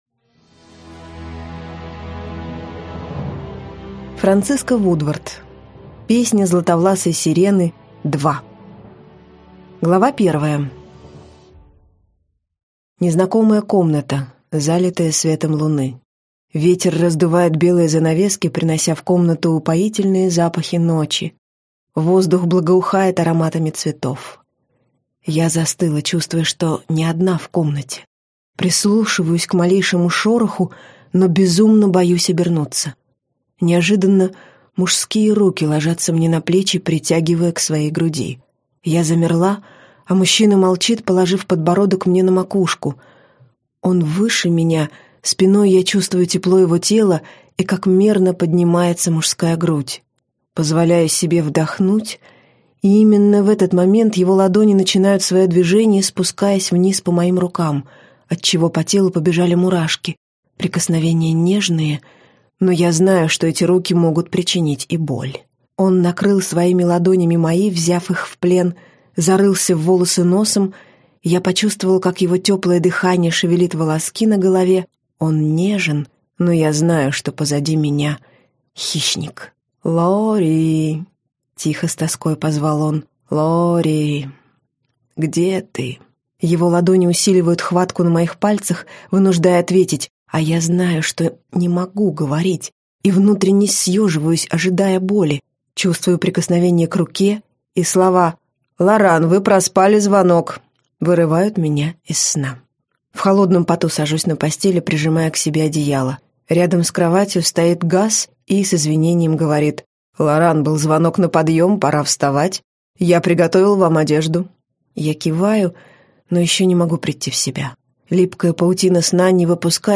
Аудиокнига Песнь златовласой сирены. Дыхание ветра - купить, скачать и слушать онлайн | КнигоПоиск